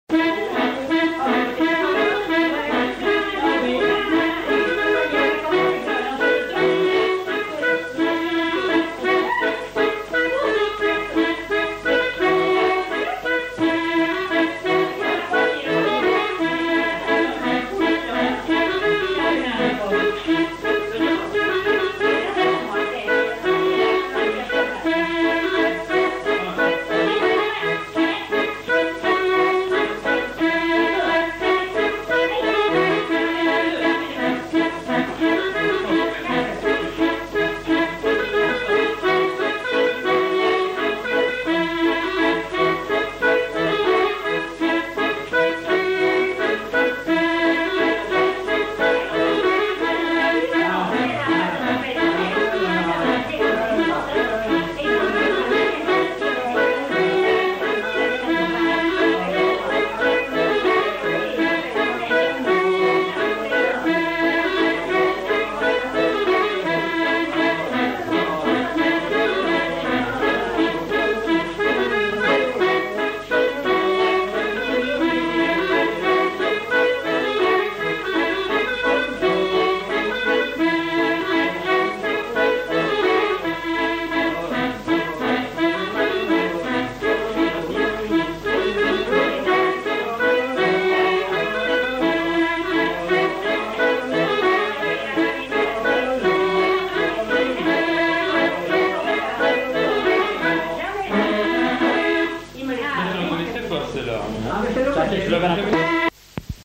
Répertoire de danses joué à l'accordéon diatonique
enquêtes sonores
Scottish